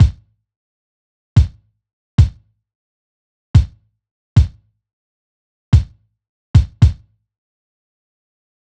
Unison Funk - 6 - 110bpm - Kick.wav